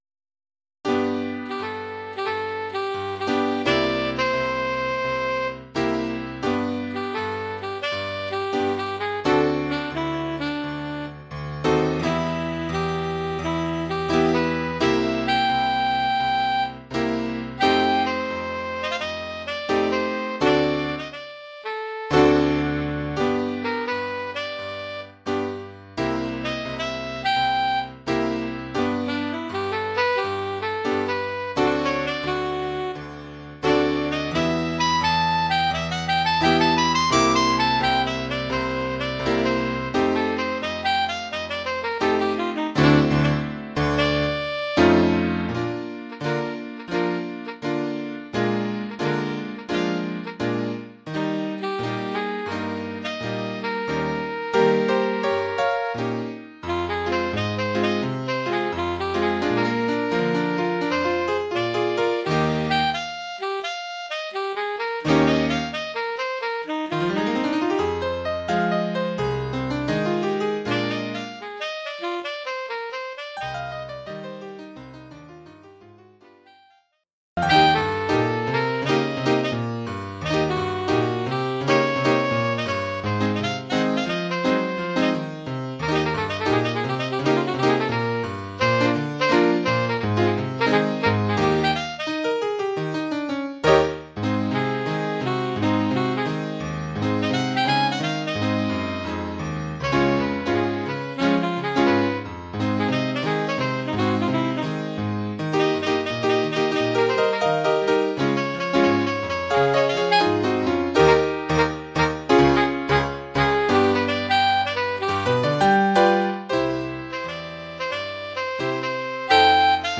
Formule instrumentale : Saxophone sib et piano
Oeuvre pour saxophone sib et piano.